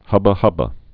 (hŭbə-hŭbə)